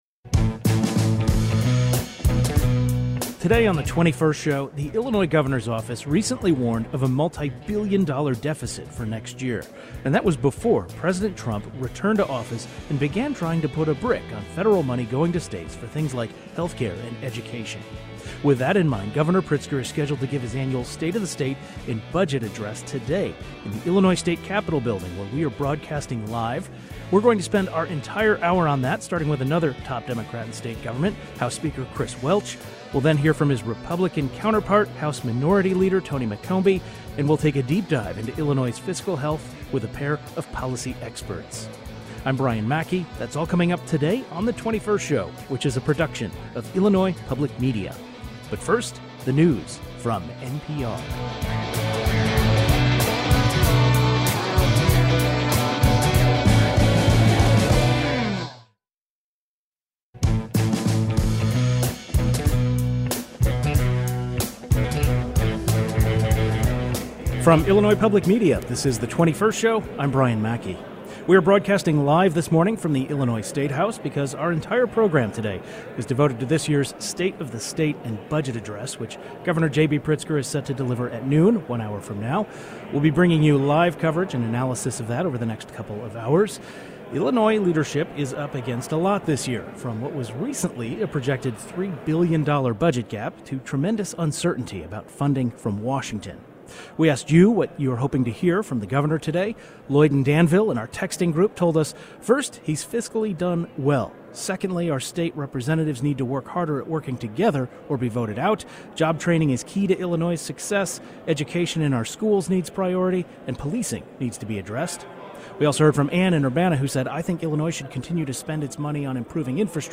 Two prominent Illinois lawmakers and some policy experts join us during our live broadcast from the Illinois Statehouse to discuss the state's finances and concerns about federal funding cuts.